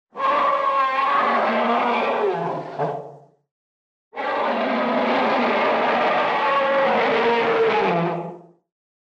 Слон громко трубит в полный хобот